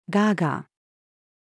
quacking-female.mp3